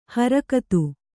♪ haakatu